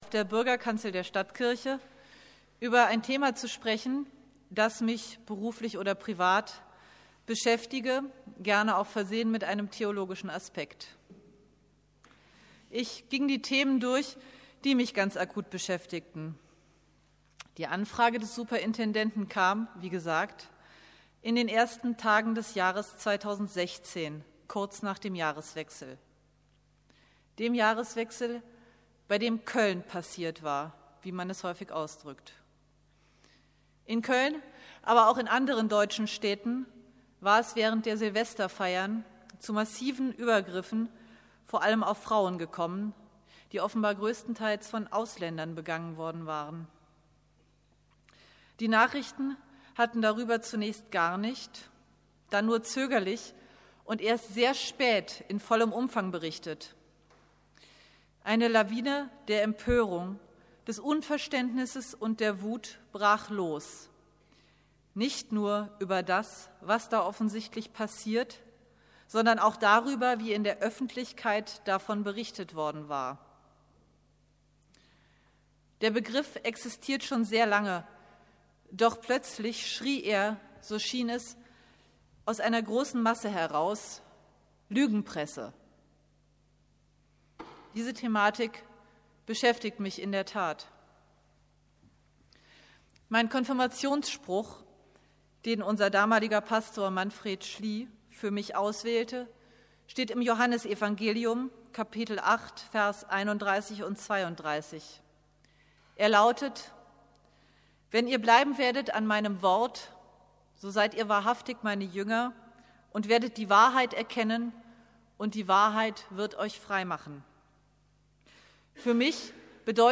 Predigt 10.Jan.2016 Das Audio-Element wird von Ihrem Browser nicht unterstützt.